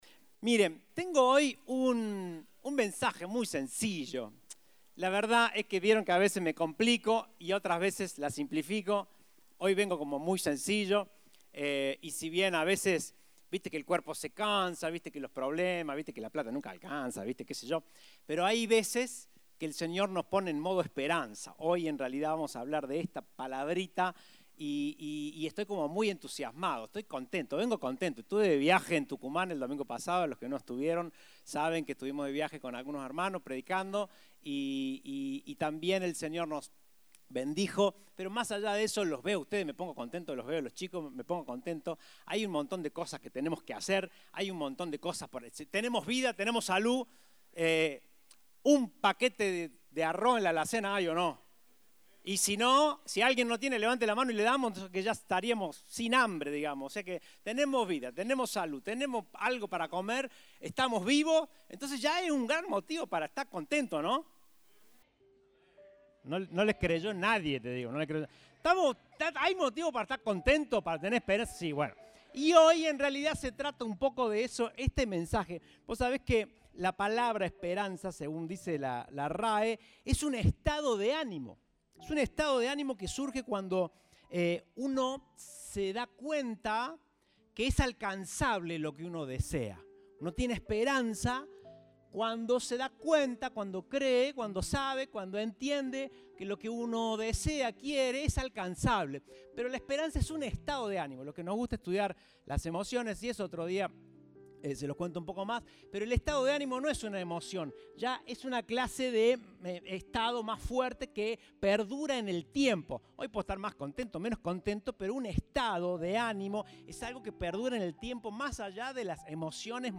Compartimos el mensaje del Domingo 3 de Julio de 2022.